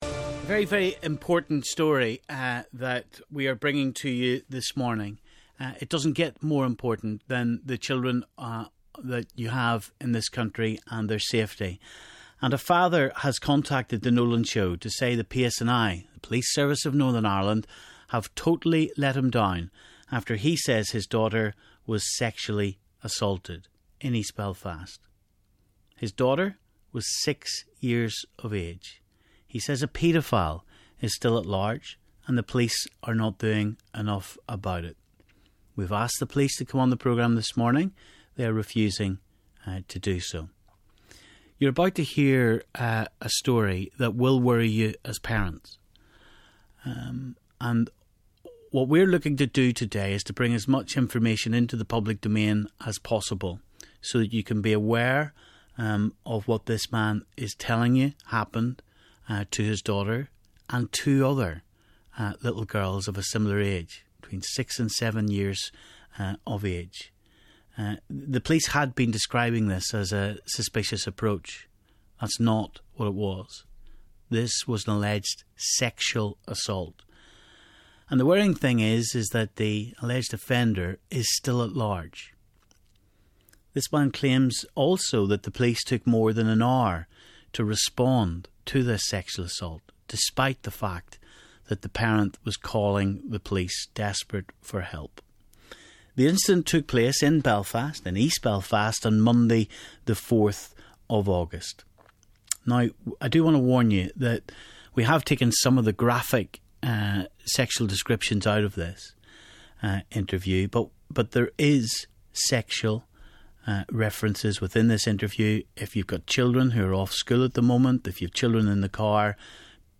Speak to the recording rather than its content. We have re-voiced the interview, to protect the identities of those involved.